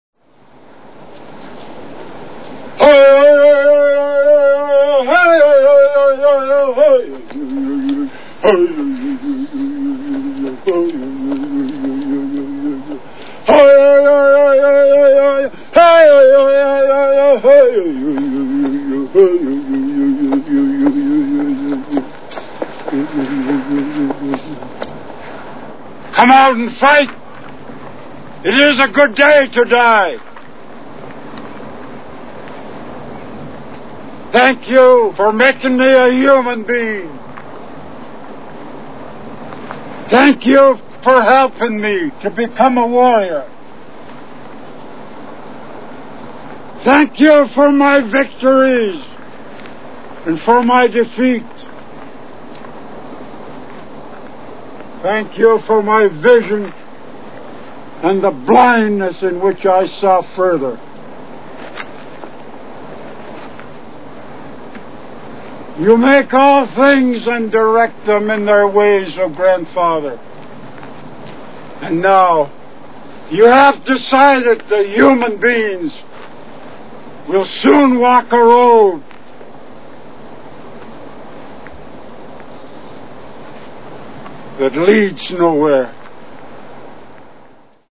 Little Big Man Movie Sound Bites